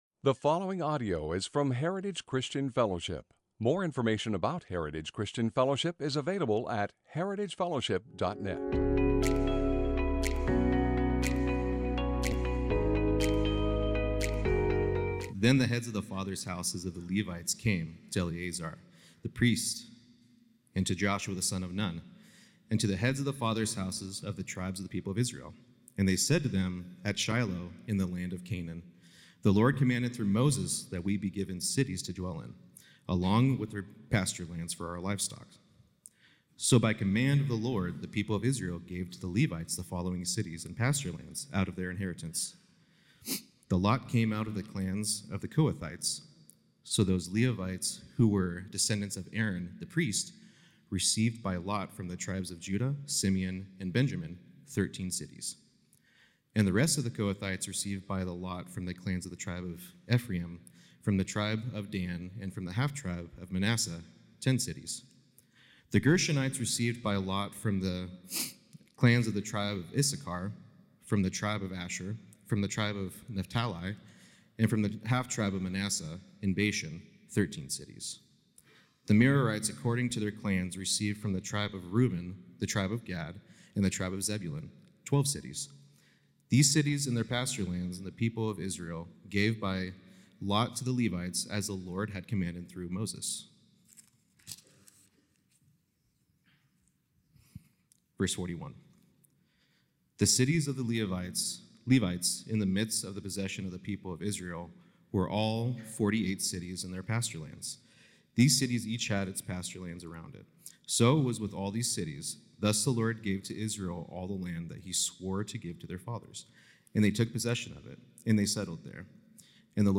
Sermons - Heritage Christian Fellowship | Of Medford, OR